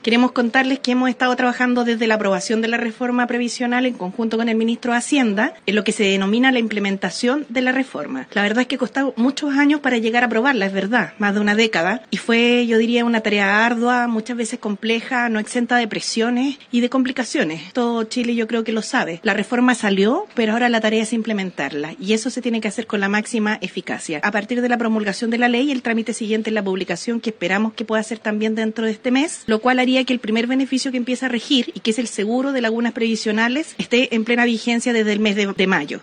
La ministra del Trabajo, Jeannette Jara, explicó que, si la publicación en el Diario Oficial se concreta este mes, los primeros beneficios comenzarán a regir en mayo.